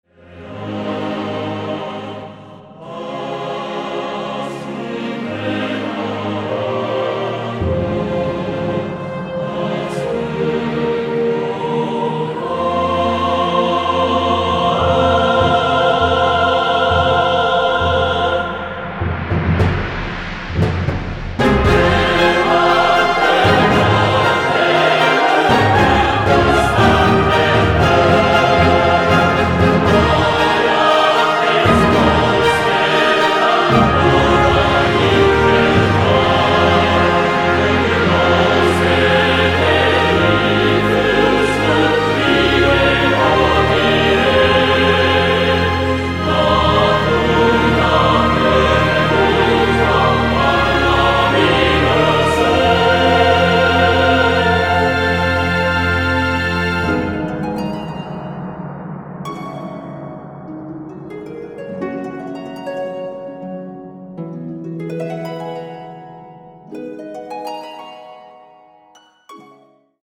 混声合唱